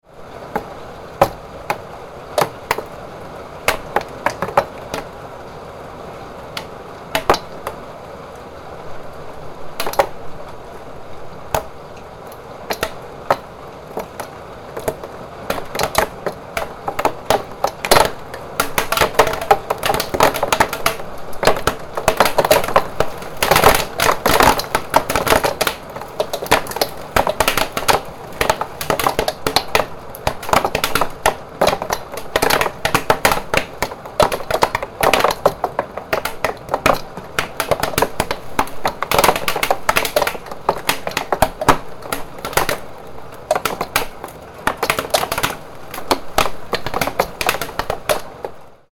Hail Hitting A Window: Summer Storm Sounds
Description: Hail hitting a window sound effect. Summer hailstorm with large hailstones hitting closed window shutters. Intense weather sound effect. Storm sounds.
Hail-hitting-a-window-sound-effect.mp3